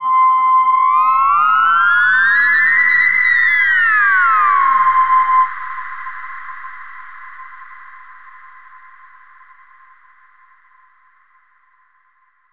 Theremin_Swoop_06.wav